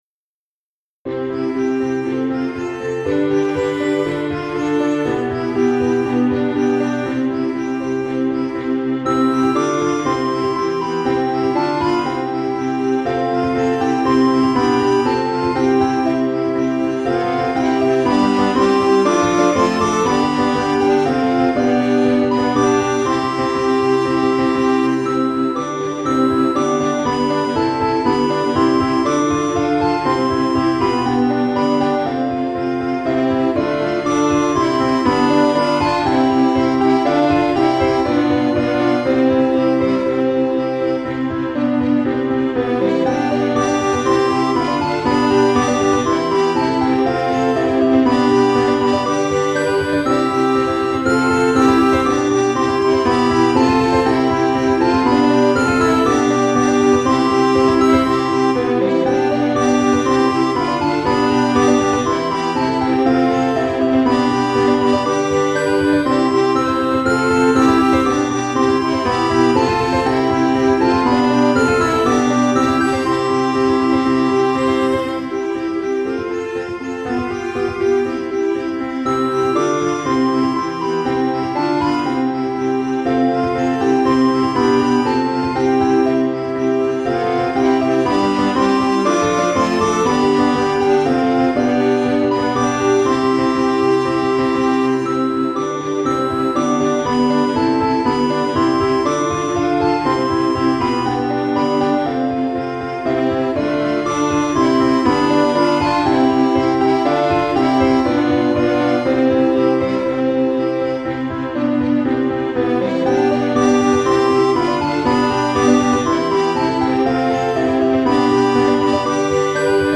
ハ長調